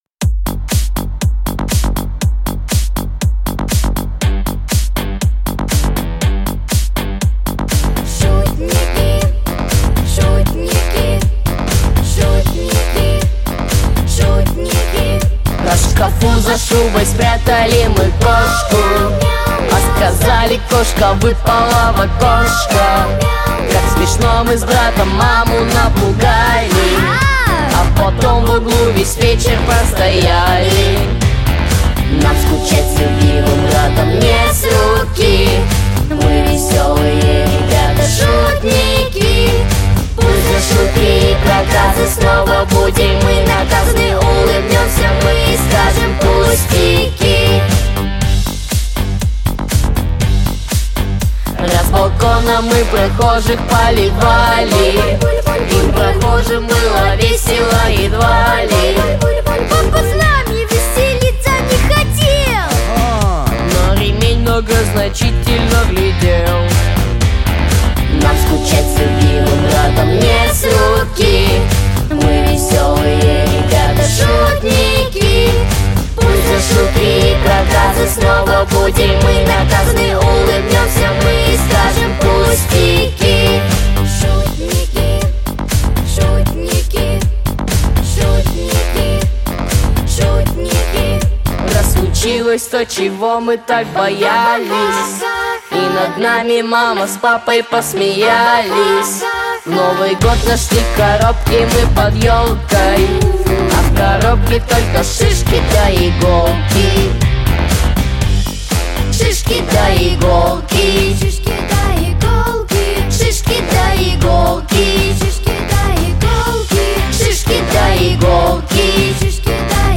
🎶 Детские песни / Песни на Новый год 🎄